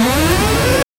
quick-exploding-sound-buz-jqoktscy.wav